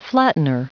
Prononciation du mot flattener en anglais (fichier audio)
Prononciation du mot : flattener